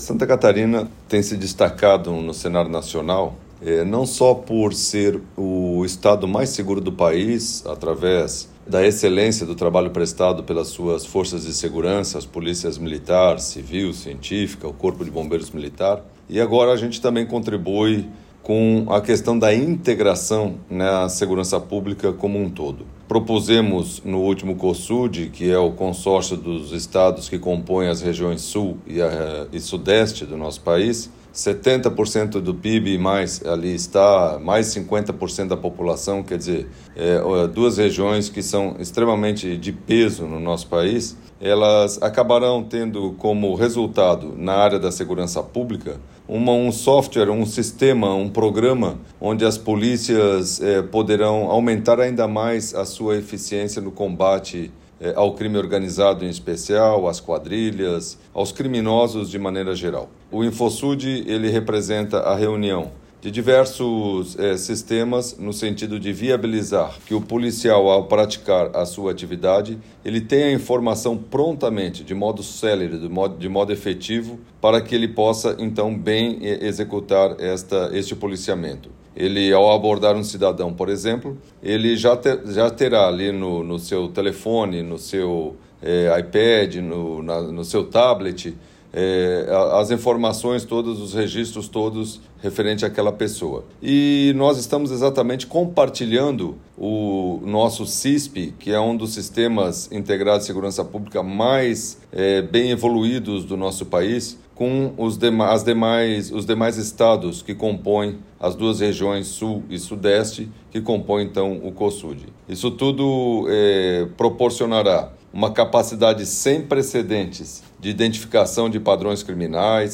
Na prática, o Infosud vai facilitar o compartilhamento de dados como registros de ocorrências, informações criminais e imagens de videomonitoramento, fortalecendo a cooperação interestadual para combate ao crime organizado, como explica o secretário de Estado da Segurança Pública, Flávio Graff: